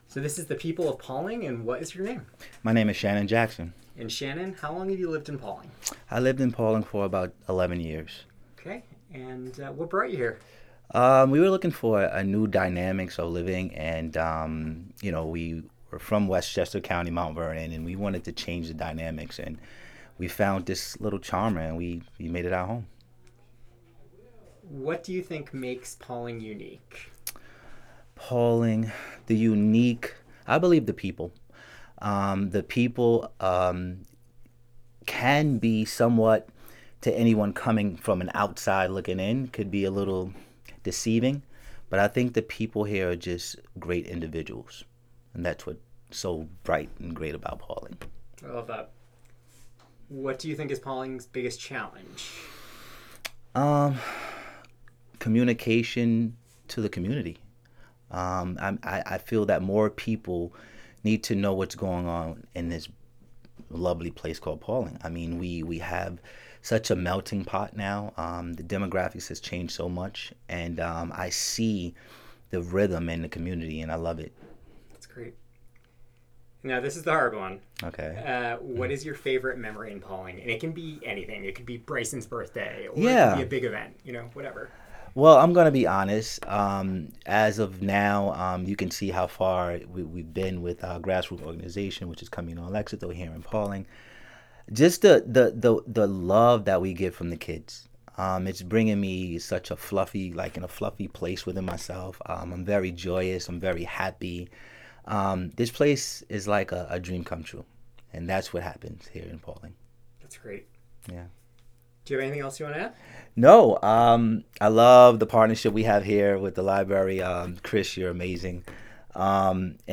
The conversation was recorded as part of the People of Pawling Project. The project saught to create brief snapshots of Pawling that could be easily consumed and show the richness of the community during the time that the recordings were done.